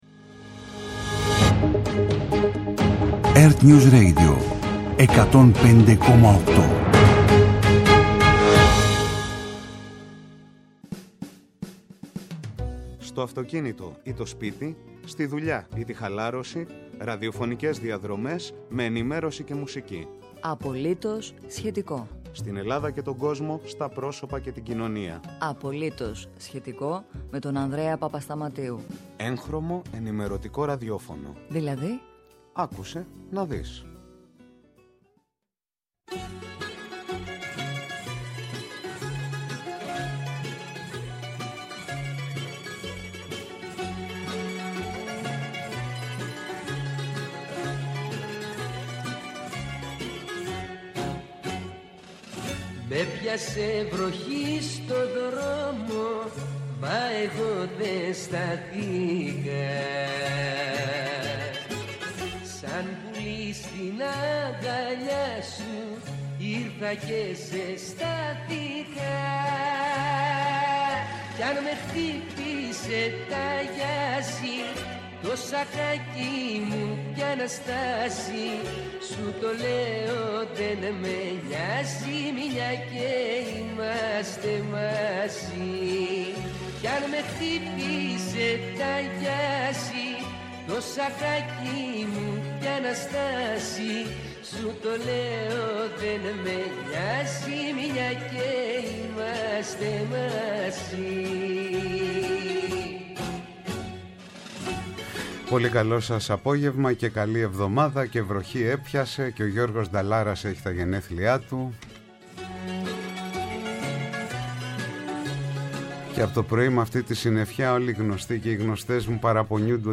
-Ο Νίκος Τσιλίφης, Δήμαρχος Δάφνης-Υμηττού για την κινητοποίηση των κατοίκων της περιοχής και των γύρω Δήμων για το κυκλοφοριακό και τα διογκωμένα προβλήματα κυκλοφορίας στα νότια προάστια